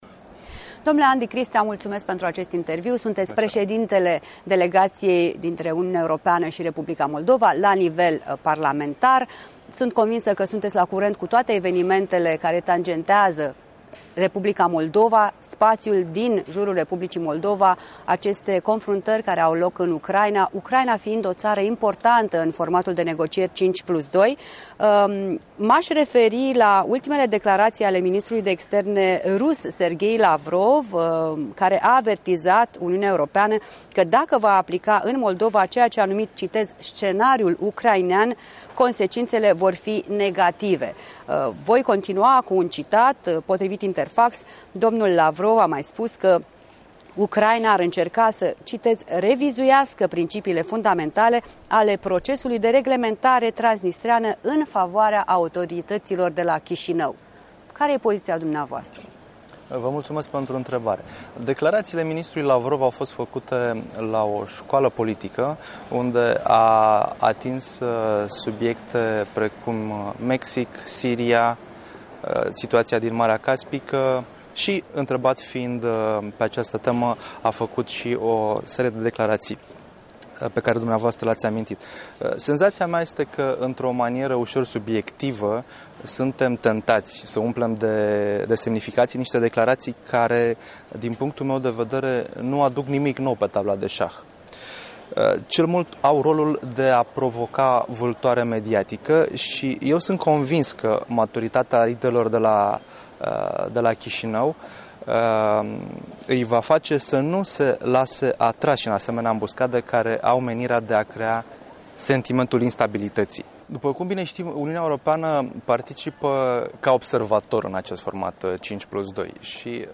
În direct de la Strasbourg cu europarlamentarul Andi Cristea